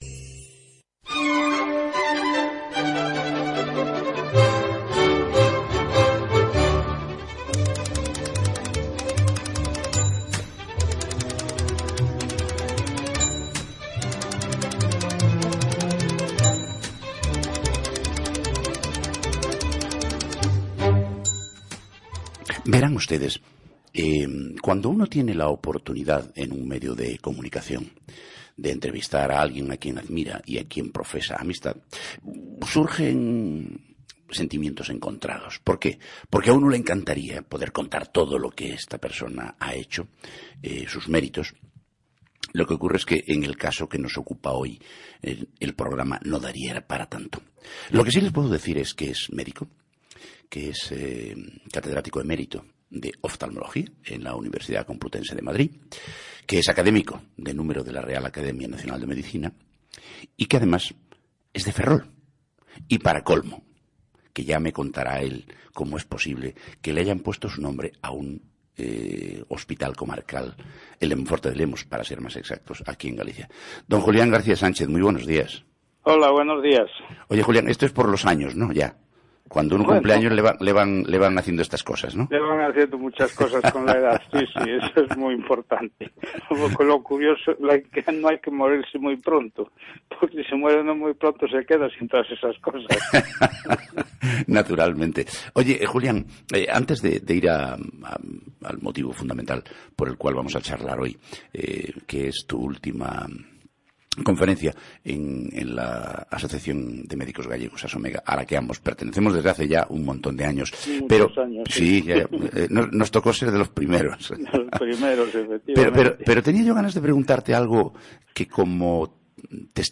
entrevistado en esRadio Galicia